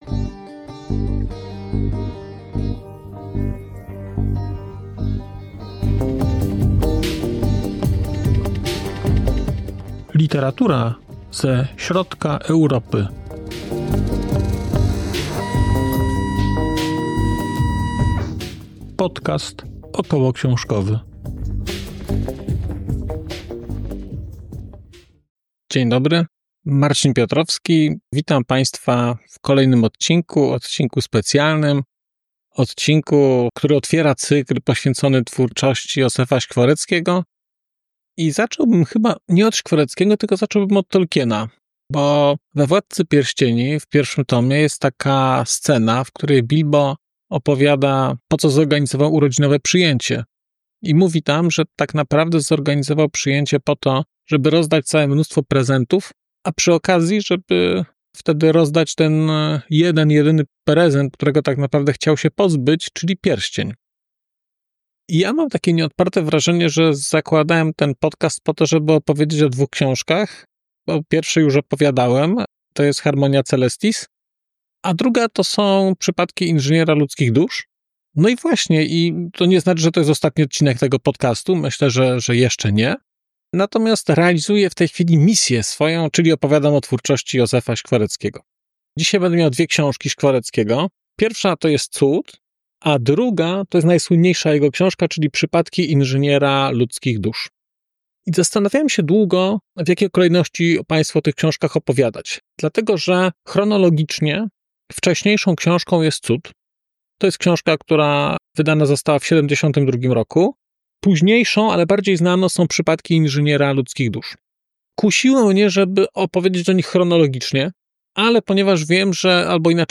🔧 materiał zrematerowany: 15.03.2025